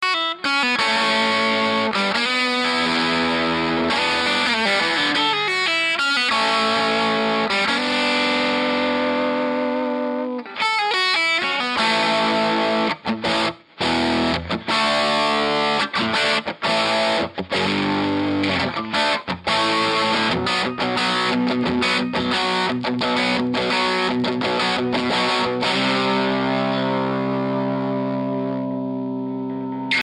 I recorded it using a Lambda pre-box and Shure SM57.
The guitar is a faded les paul double cut with old(ish) P-90's. It is a recent acquisition and sounds very nice.
The guitar was plugged into the bright channel input 2.....the volume was pretty much cranked with the treble on 8, mids on 3-4 and bass on 0.
I Have a Marshall bluesbreaker RI and it does not have the bold strident tone or chunky bottom that the Trinity has.
The SM57 was right at the grillcloth at the Alnico Tone Tubby.